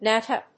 意味・対訳 ナッタ